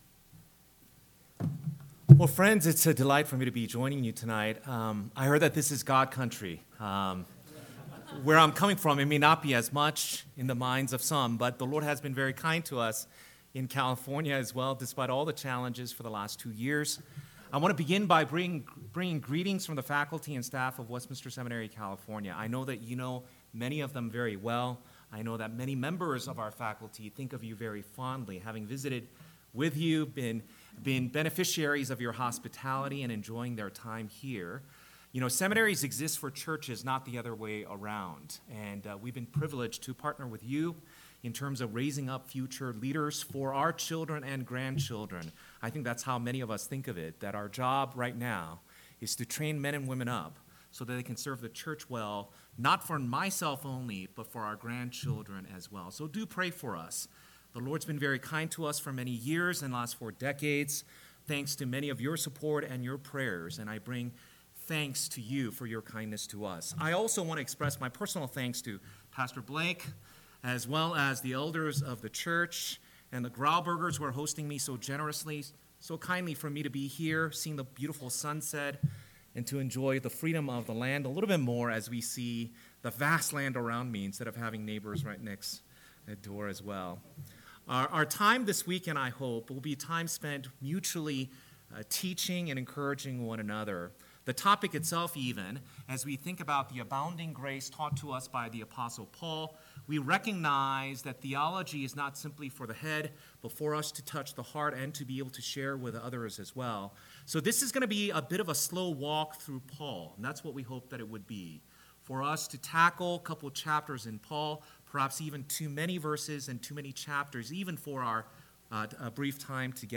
2021 Cheyenne Reformation Conference Session 1 – Northwoods Sermons